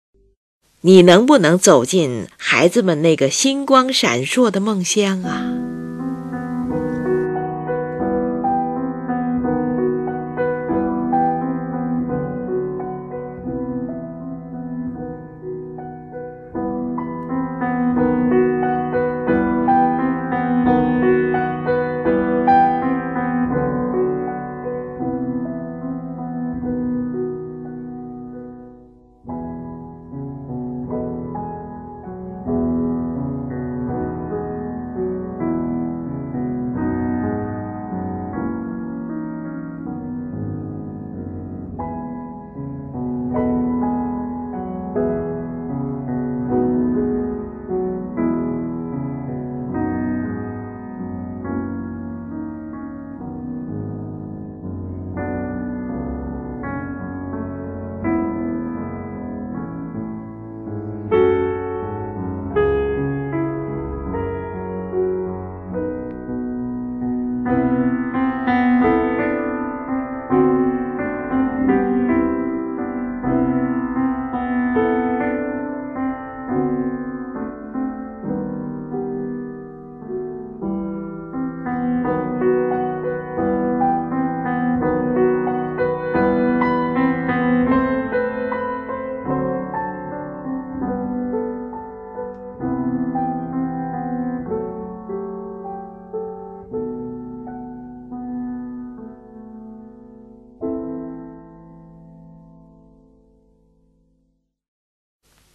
是钢琴艺术史上的一部极为独特的作品
其间，从原先的G大调突然向E大调转换，更增添了乐曲的梦幻色彩。